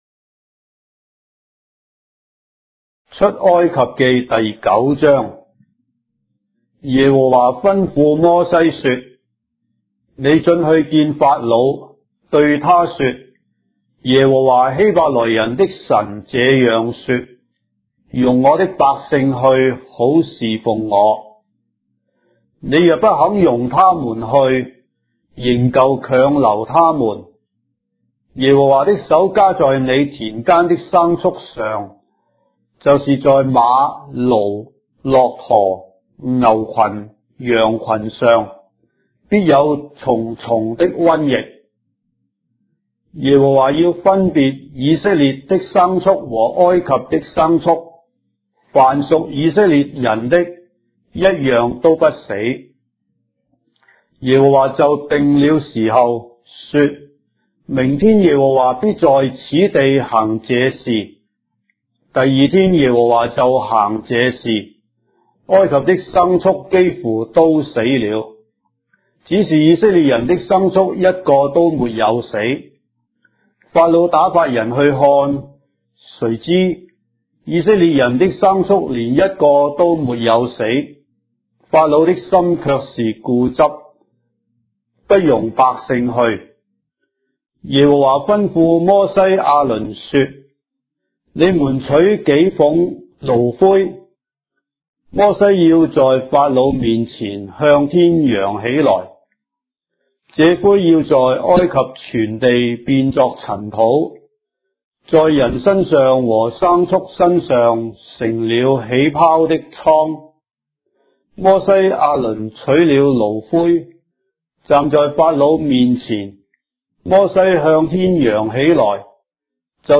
章的聖經在中國的語言，音頻旁白- Exodus, chapter 9 of the Holy Bible in Traditional Chinese